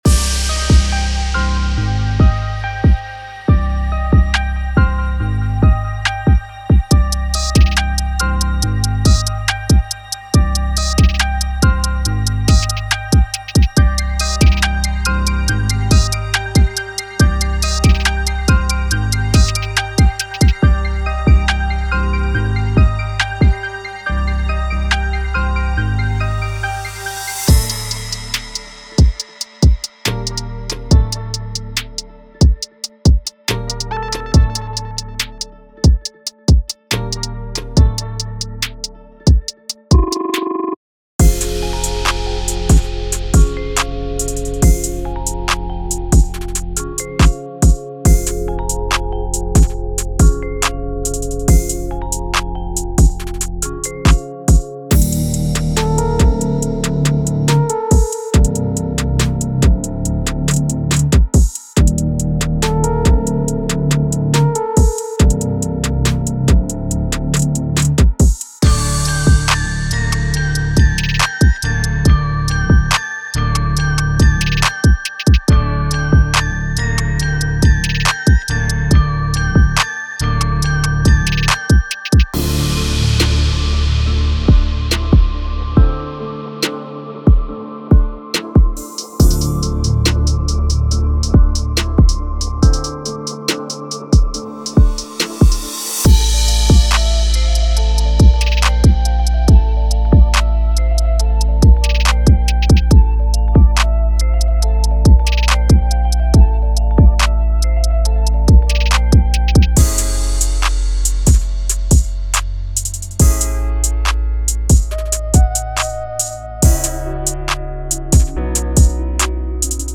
Hip-Hop / R&B Trap